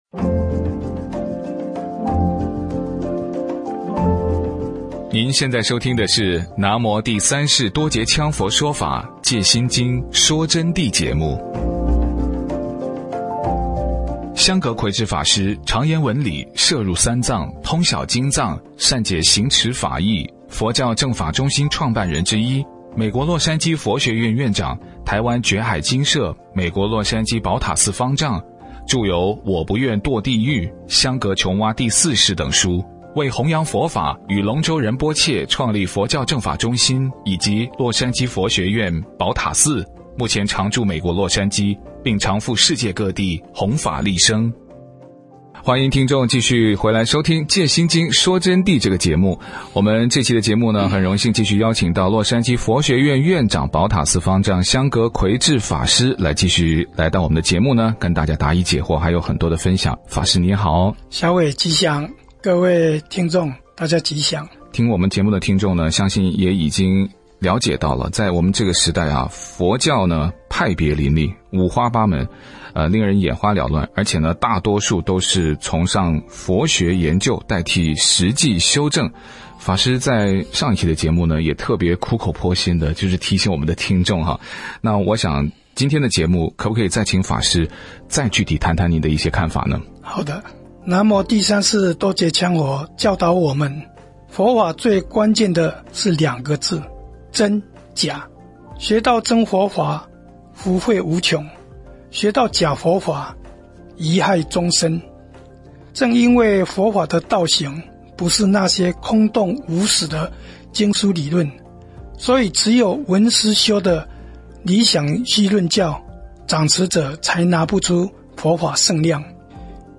佛弟子访谈（二十五）一月十九佛陀日的因缘和如何脱离因果束缚解脱轮回？